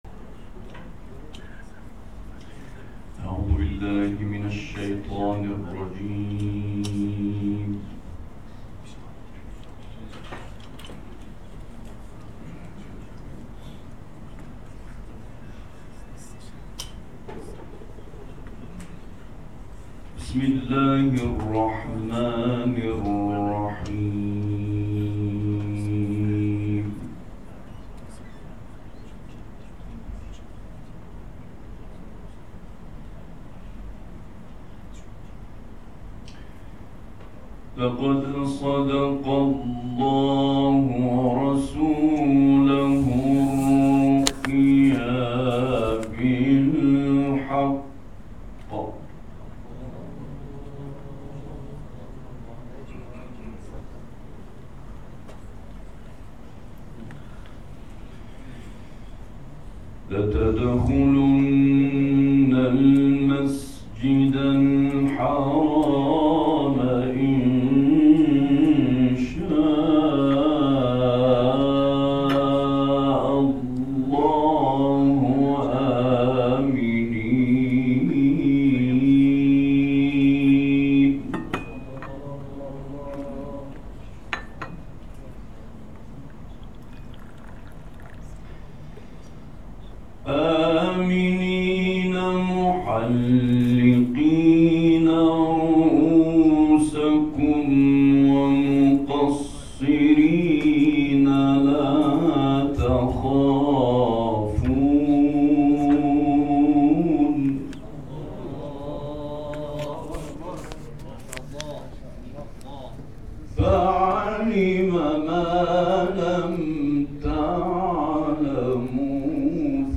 جدیدترین تلاوت سعید طوسی + دانلود
گروه فعالیت‌های قرآنی: سعید طوسی در دومین روز از هفتمین دوره آموزشی ـ توجیهی کاروان قرآنی اعزامی به حج تمتع‌ به تلاوت آیاتی از قرآن پرداخت.